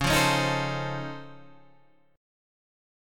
Db+M9 Chord
Listen to Db+M9 strummed